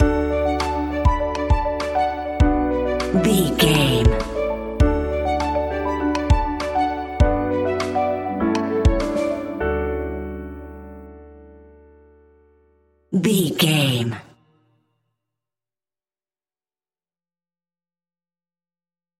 royalty free music
Ionian/Major
D
pop rock
indie pop
fun
energetic
uplifting
upbeat
groovy
bass
drums